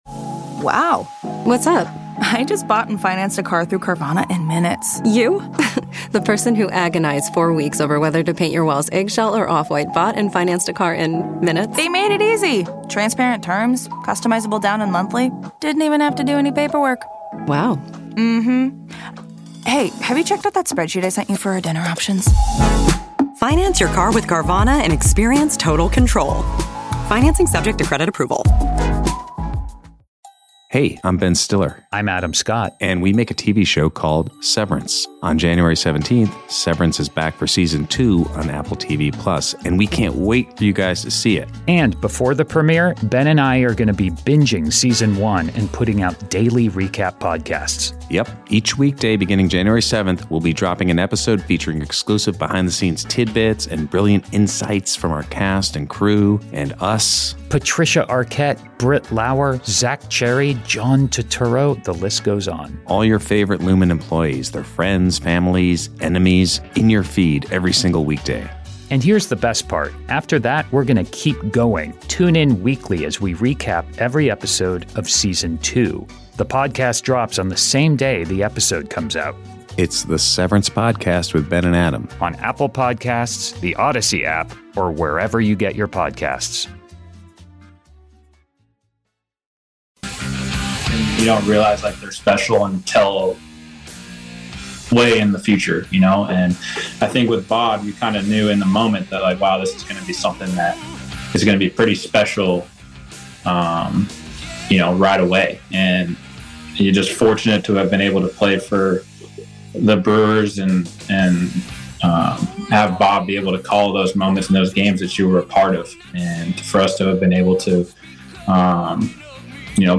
Brewers Coverage / 01-17-25 The Mason Crosby Show Interviews